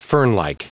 Prononciation du mot fernlike en anglais (fichier audio)
Prononciation du mot : fernlike